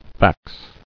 [fax]